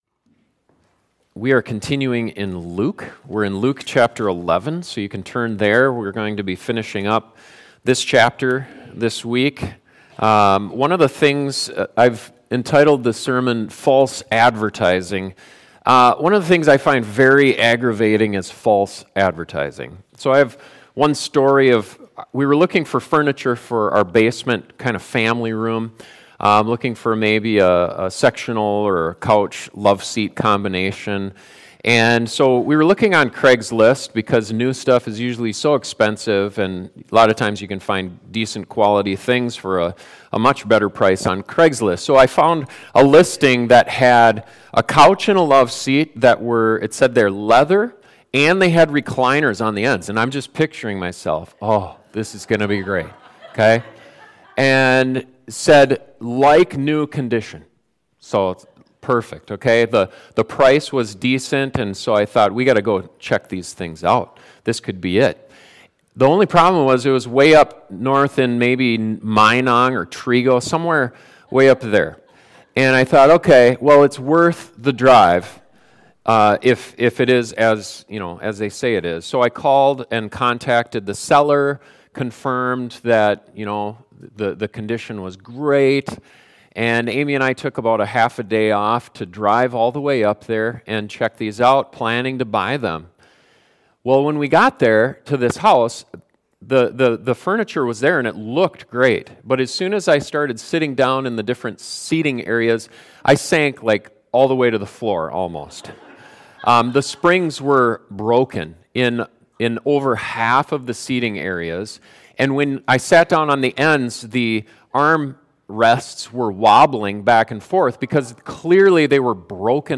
One of the things that Jesus confronted very strongly during his years of ministry was the hypocrisy of the scribes and Pharisees. This sermon helps us examine our own lives to see if the same sin is present in us and how we can avoid it.